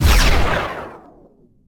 enemybeams.ogg